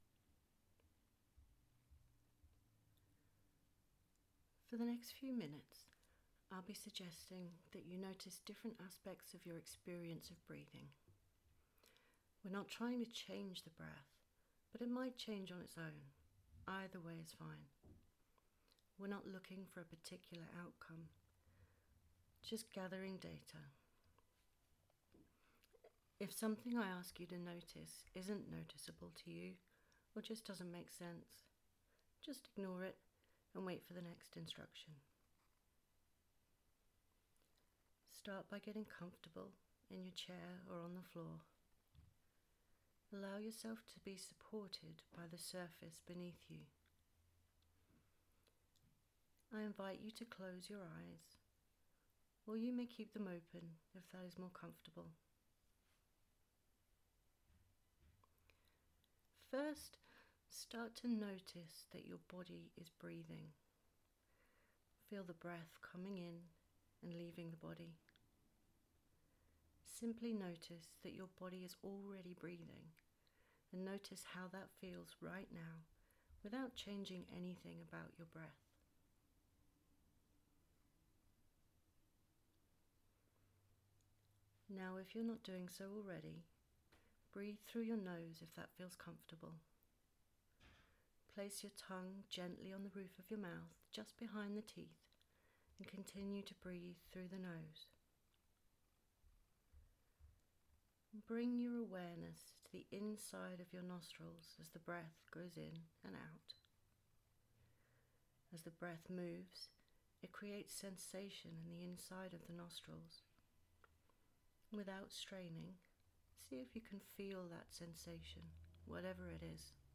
Here is a short guided meditation designed to bring awareness to the breath, and encourage you to explore how your breath can affect sensation, and potentially your experience of pain.